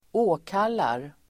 Ladda ner uttalet
Uttal: [²'å:kal:ar]